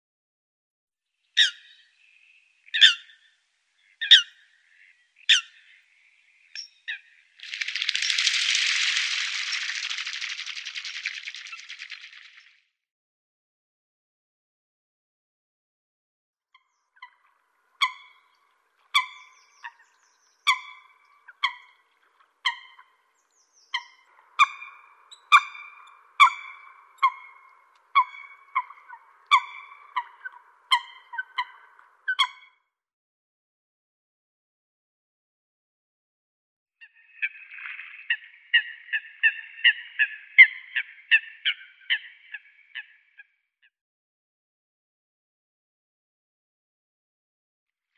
دانلود آهنگ چنگر نوک سفید از افکت صوتی انسان و موجودات زنده
دانلود صدای چنگر نوک سفید از ساعد نیوز با لینک مستقیم و کیفیت بالا
جلوه های صوتی